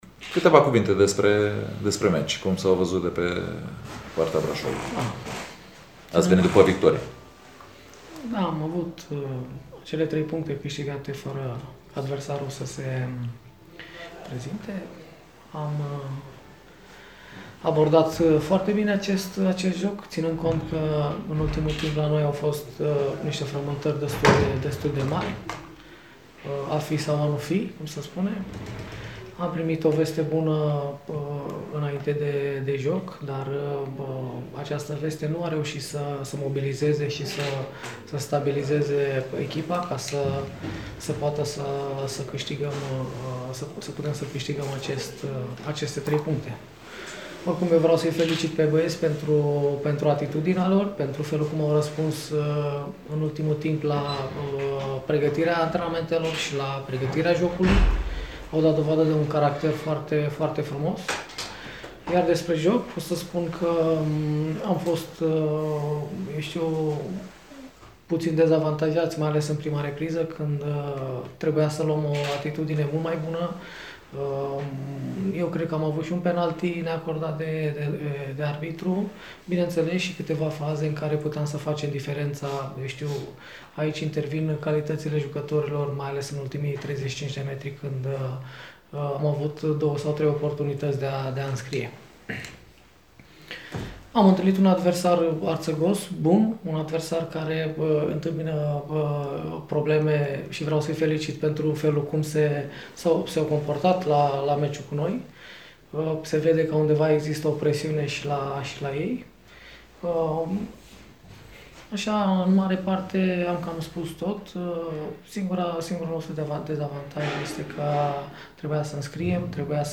Imediat după meci, antrenorul secund al braşovenilor, fostul internaţional Florentin Petre, şi-a felicitat elevii pentru jocul făcut împotriva Forestei: